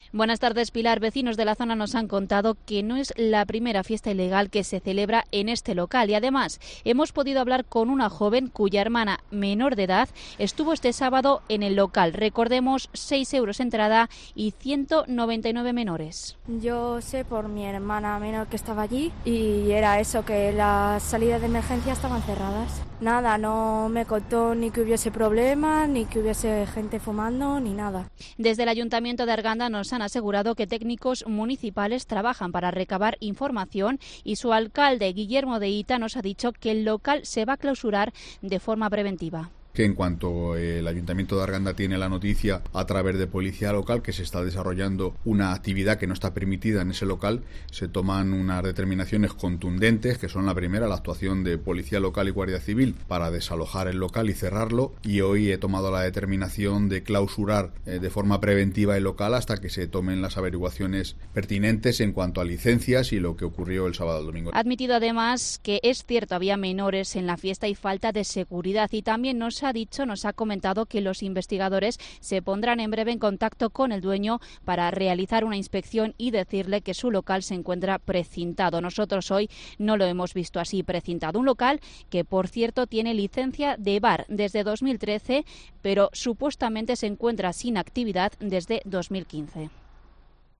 El alcalde de la localidad se defiende en COPE: "En cuanto supimos que se hacía algo ilegal, cerramos el local"
El alcalde de Arganda, el socialista Guillermo Hita, se ha defendido este lunes ante los micrófonos de COPE.